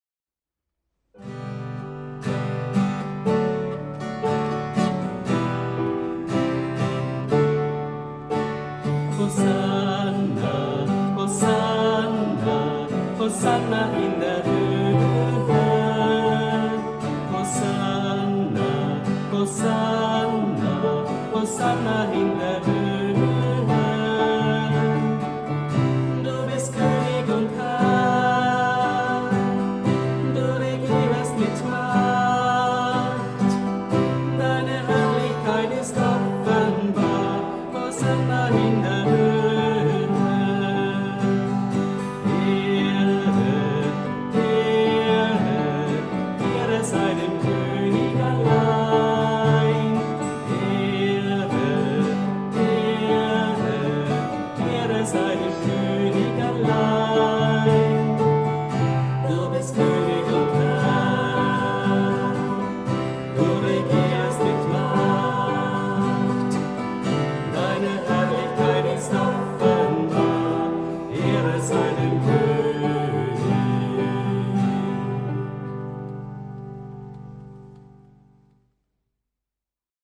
2020-09-27 Erntedankmesse